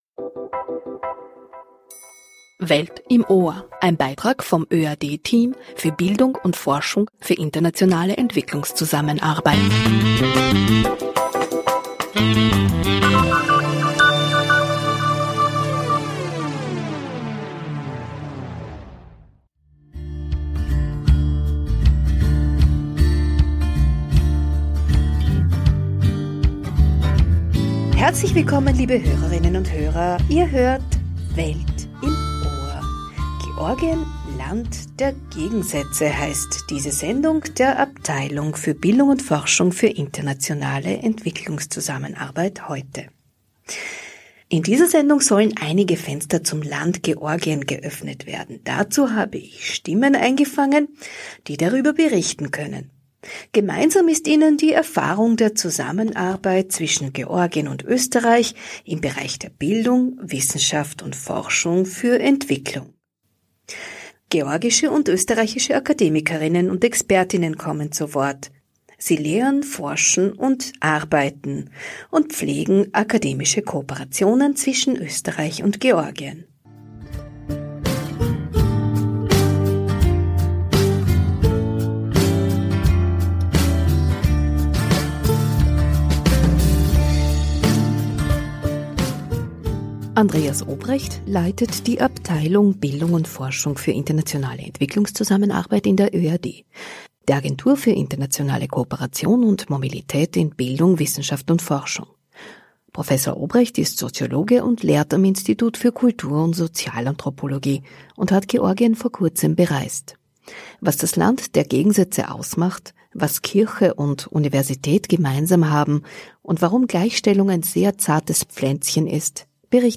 Zwischen Sowjet-Nostalgie und postkommunistischer Transformation: Wissenschafter/innen und Expert/innen erzählen über ihre berufliche und persönliche Erfahrungen mit österreichisch-georgischen Hochschulkooperationen.